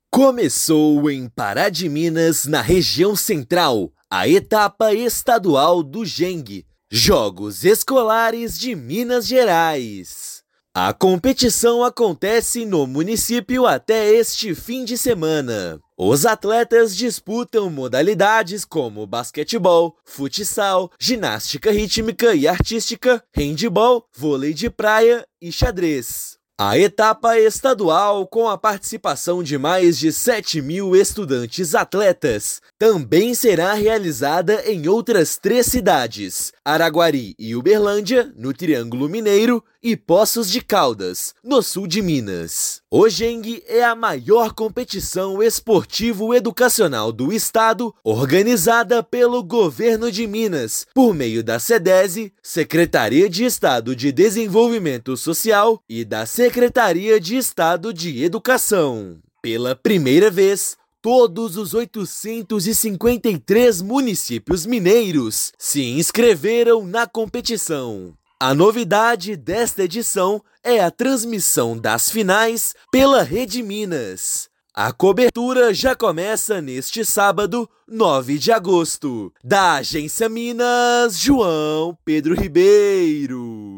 Neste ano, competição acontece em quatro cidades, com mais de 7 mil estudantes-atletas disputando diferentes modalidades. Ouça matéria de rádio.